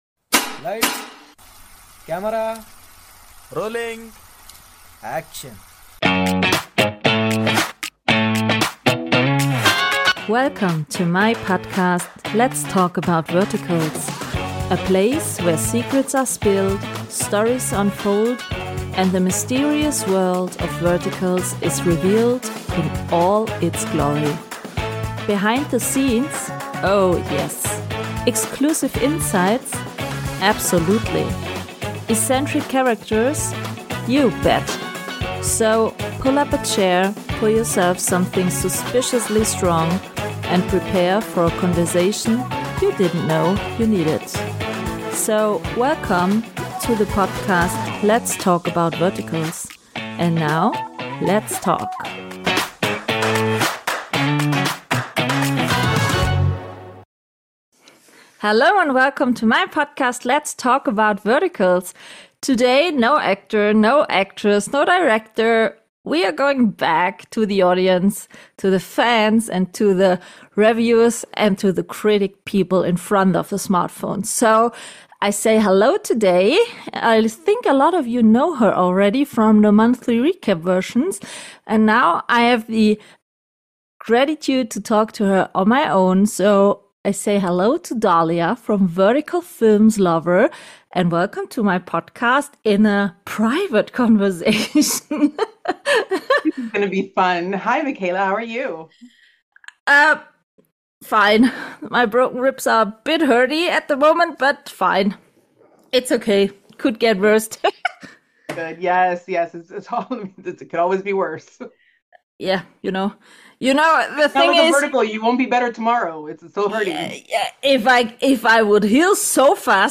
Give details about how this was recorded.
This episode is a truly wonderful conversation — critical, insightful, and definitely entertaining One you absolutely shouldn’t miss!